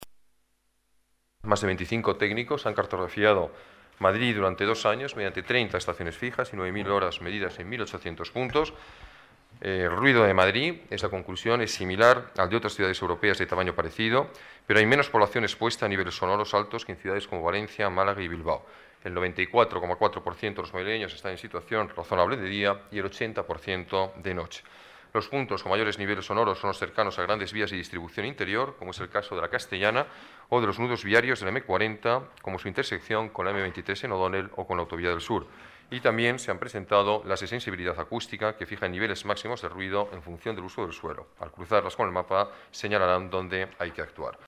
Nueva ventana:Ruiz-Gallardón informa del mapa estratégico del ruido